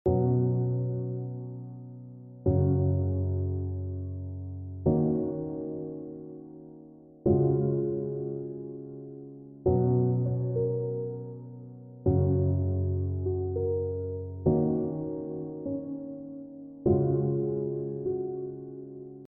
piano_prelude.wav